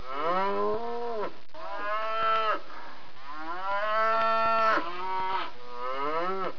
جلوه های صوتی
دانلود صدای حیوانات جنگلی 53 از ساعد نیوز با لینک مستقیم و کیفیت بالا